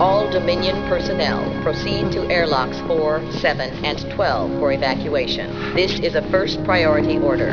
A comm announcement orders all Dominion personnel through 4, 7 and 12 to evacuate the station.